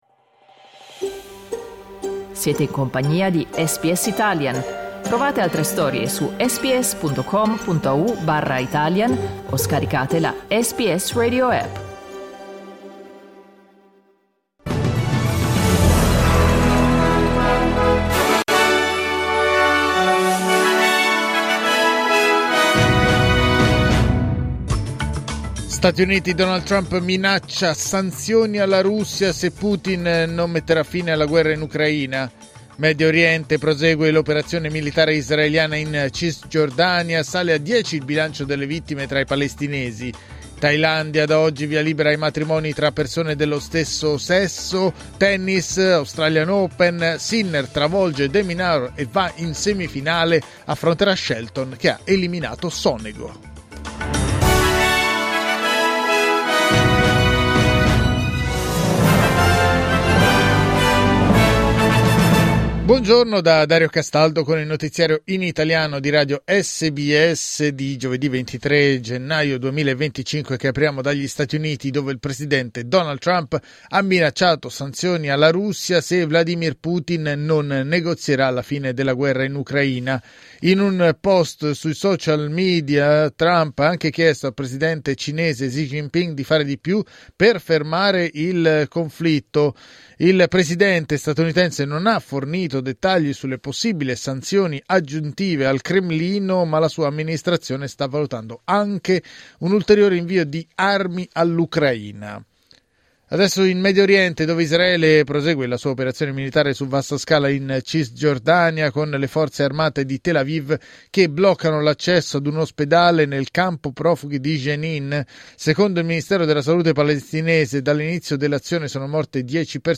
Giornale radio giovedì 23 gennaio 2025
Il notiziario di SBS in italiano.